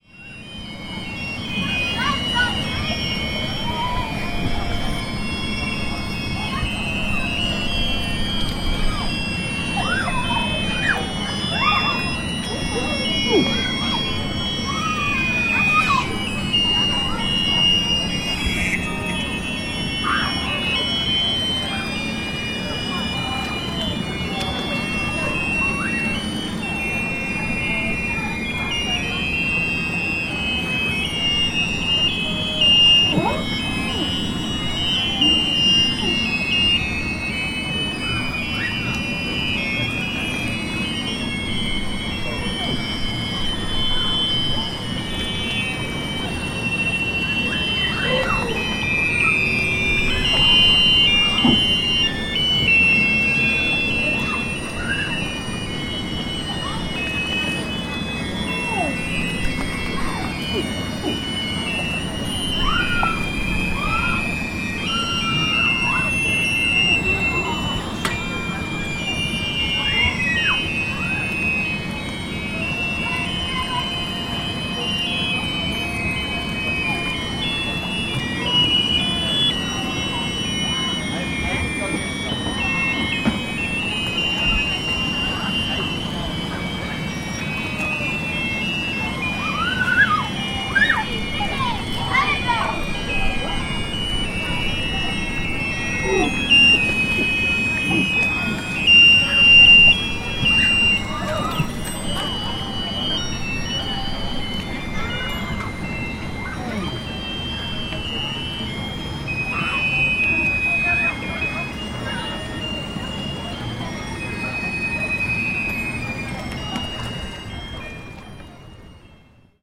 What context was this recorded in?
Herne Bay pier - boat ride melodies